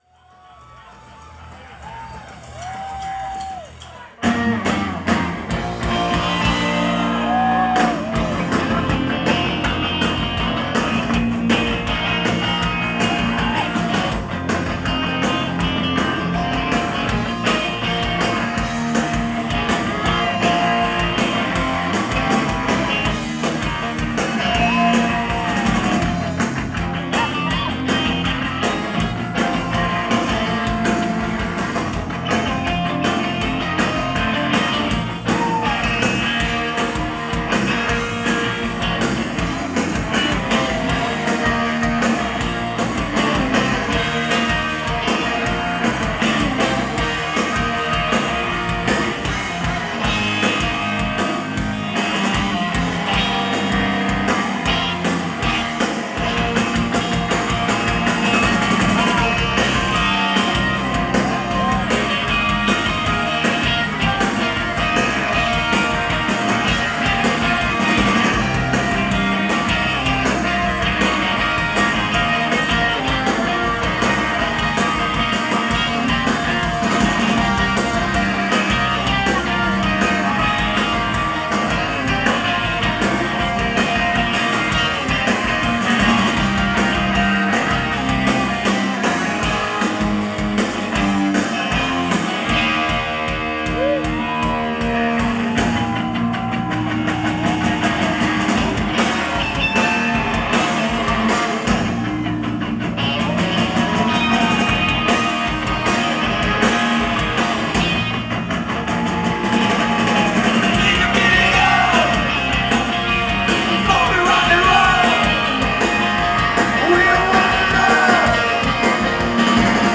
Live at the Sinclair, Cambridge, MA